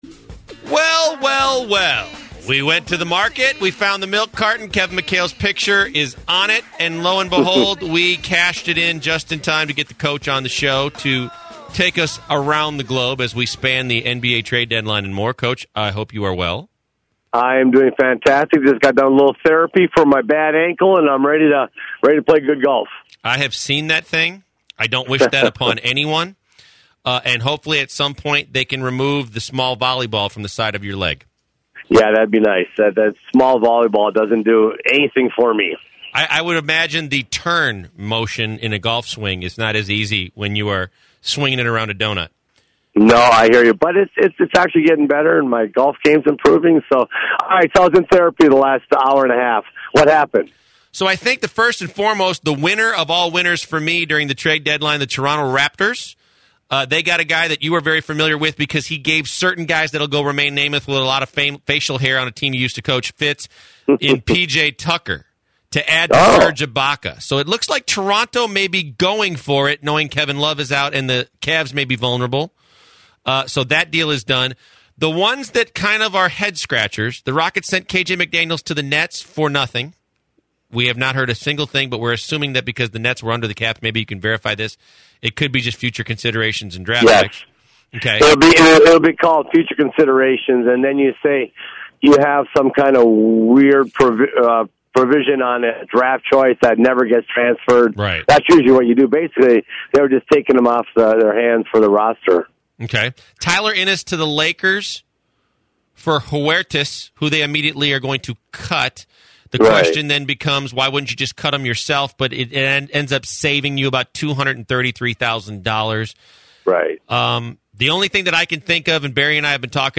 Kevin McHale joins the show to speak about the NBA trade deadline and on Magic Johnson taking over as President of Basketball Operations for the Lakers.